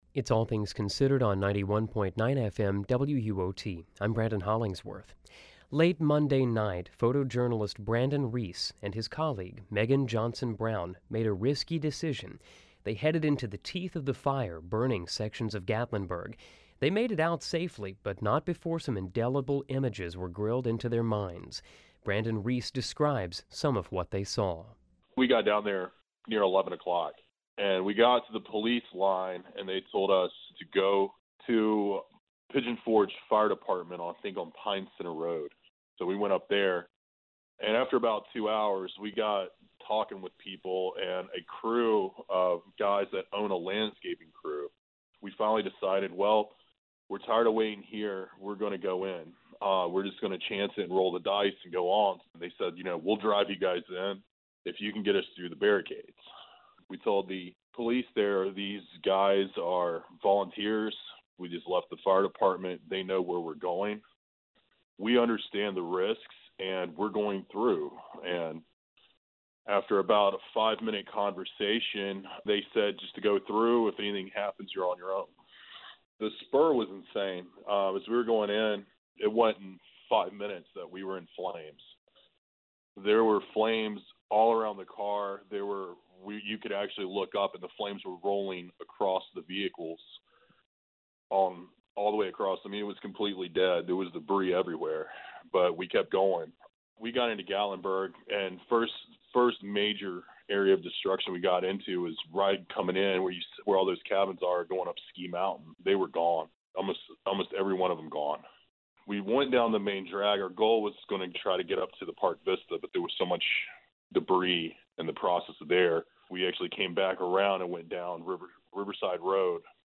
Inside The Inferno: An Eyewitness Account